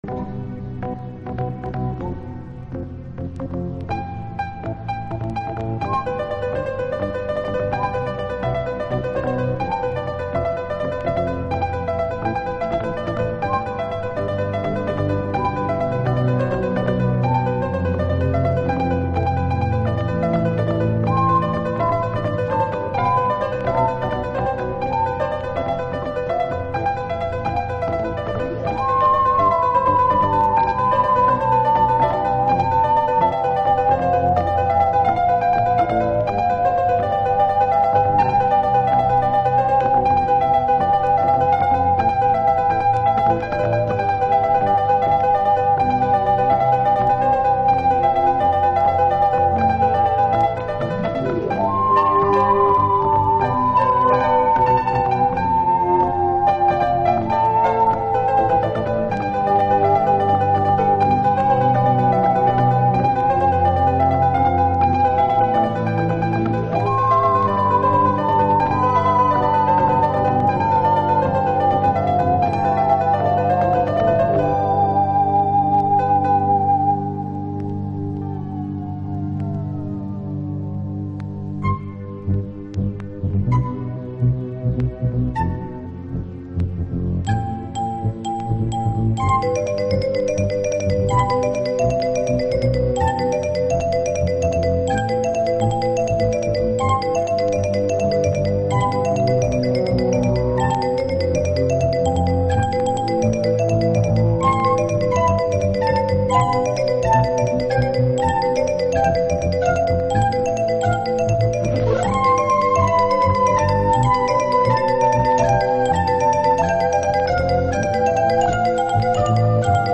まるでマイクオールドフィールドやジャンミッシェルジャールを彷彿させるトラックは当時のハウスシーンでも異形だったハズ。